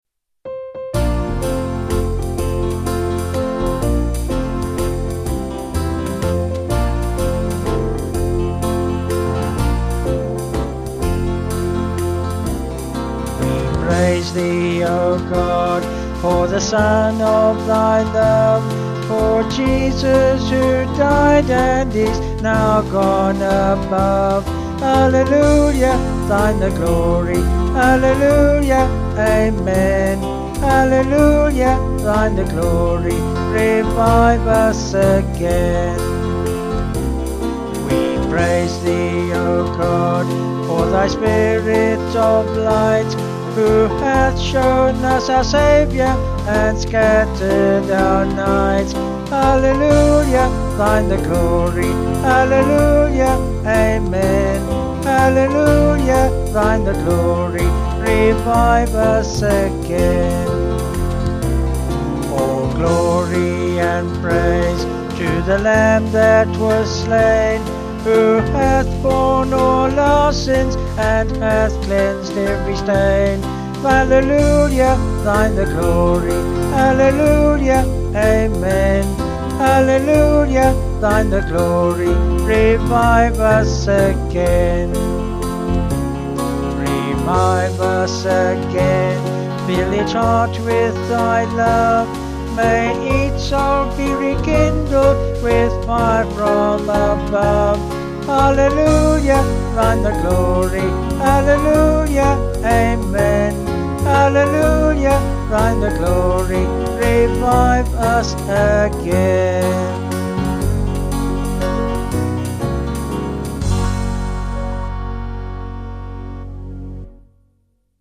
11.11. with refrain
Vocals and Band   264.4kb Sung Lyrics